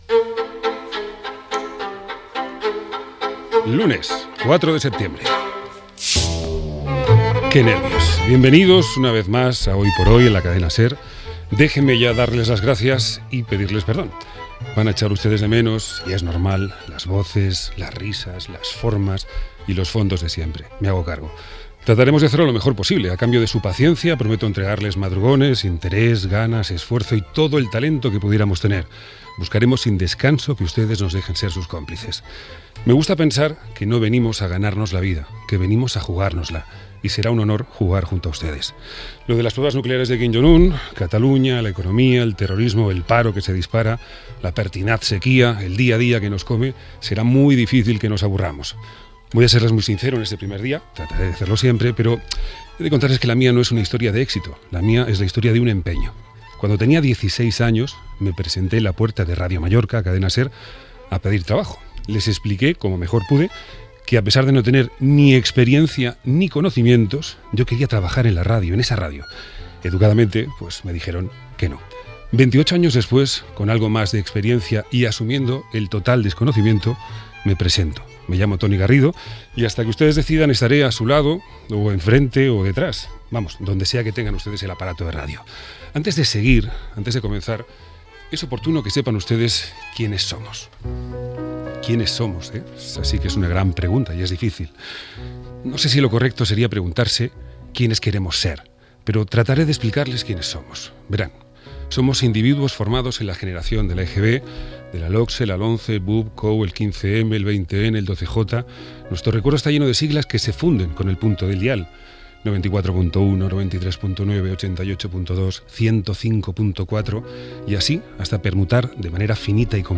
Paraules en el primer dia que Toni Garrido presenta el bloc de 10 a 12 hores del programa. Tema musical amb fragments radiofònics de diverses èpoques de la Cadena SER
Info-entreteniment